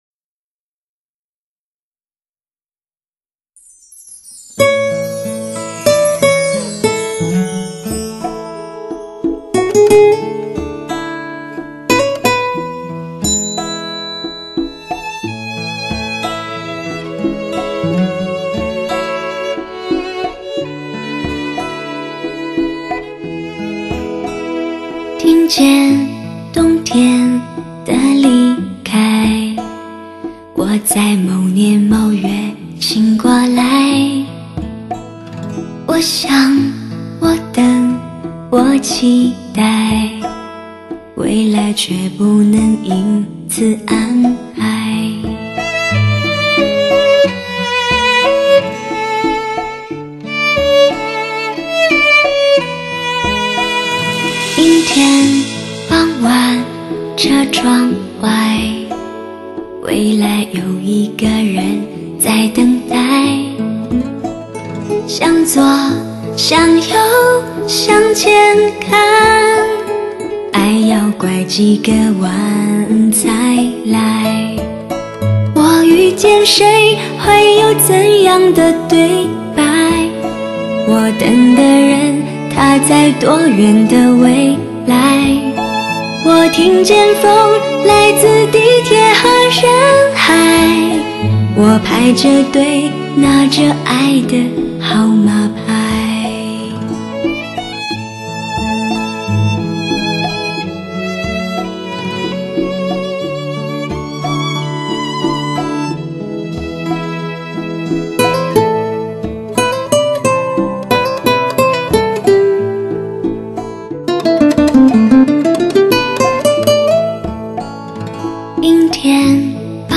独具一格的雅致轻吟，令你的听觉焕然一新。
引领发烧潮流的DTS技术，以最完美的音质和最精准的声场定位纵横发烧界。
原来，这个世界上有着这样清秀可人的音色，能醉人心扉，沁人心脾，不知归去。
聆听顶级逼真和超乎想象的环绕声音乐。
DTS绝对撩人之音，不容错失的媚色女声。